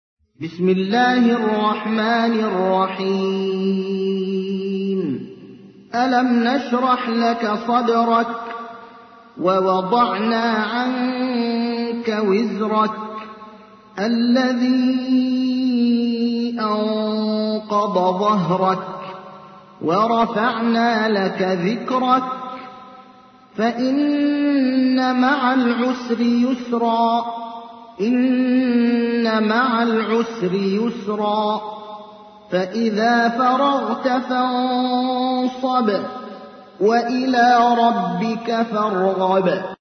تحميل : 94. سورة الشرح / القارئ ابراهيم الأخضر / القرآن الكريم / موقع يا حسين